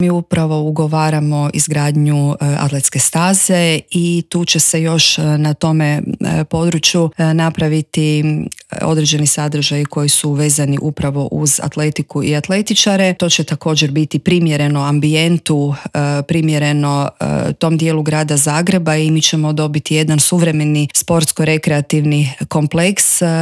ZAGREB - Uoči lokalnih izbora u razgovoru za Media servis zamjenica gradonačelnika te kandidatkinja Bandić Milan 365 Stranke rada i solidarnosti za zagrebačku gradonačelnicu Jelena Pavičić Vukičević progovorila je o obnovi Zagreba od potresa kao i kakve planove ima s Dinamovim stadionom.